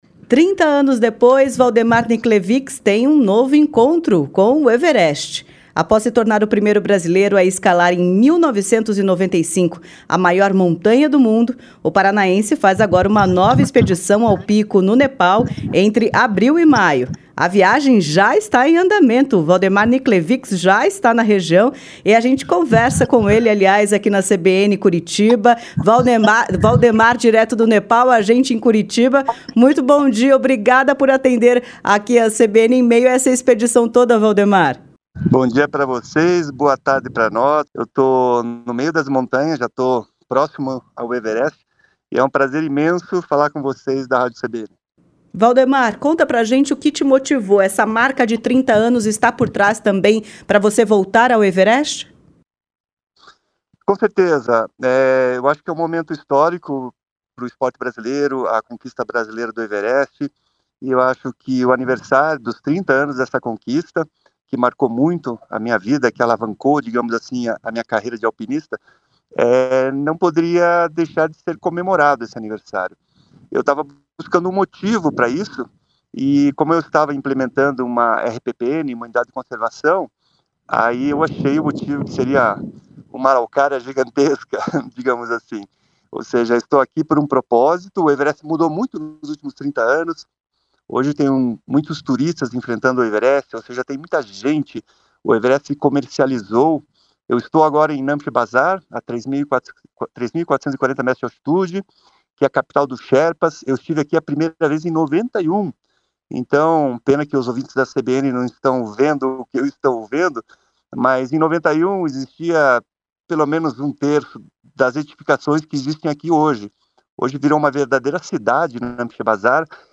ENTREVISTA-WALDEMAR-NICLEVICZ.mp3